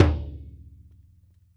SingleHit_QAS10774.WAV